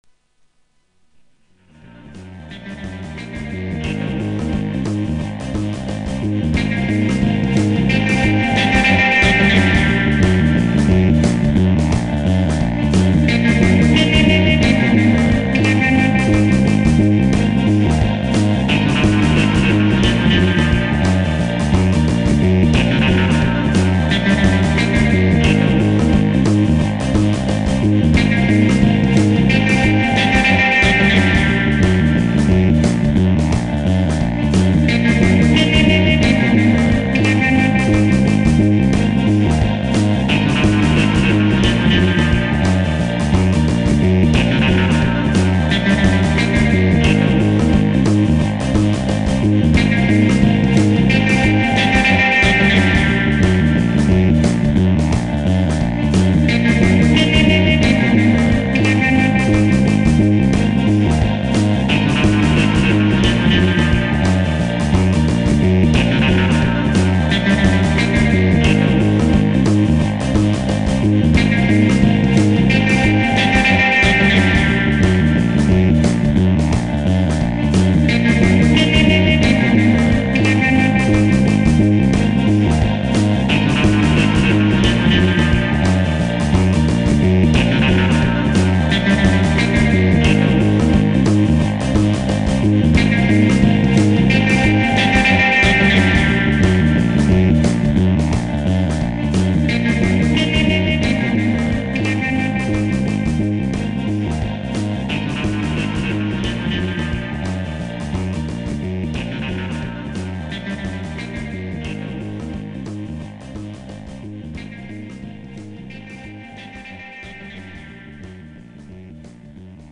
Tags: haiku surf guitar humour silly noises instrumental illness